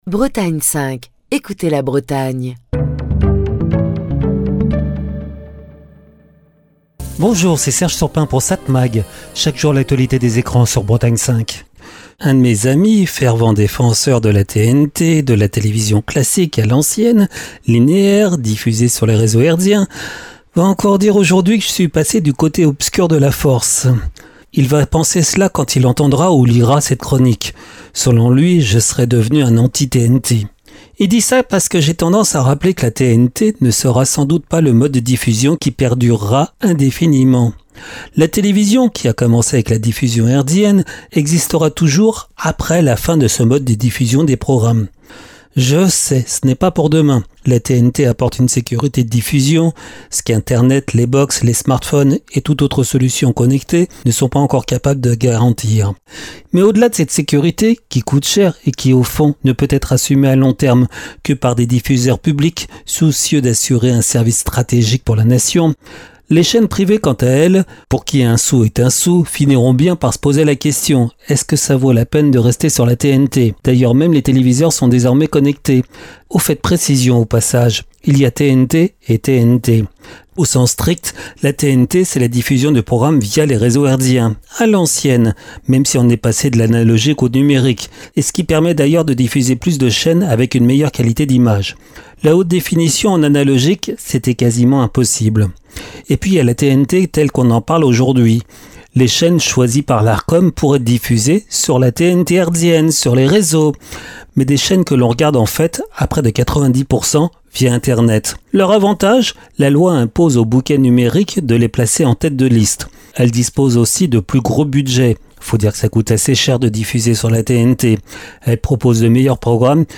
Chronique du 3 septembre 2025.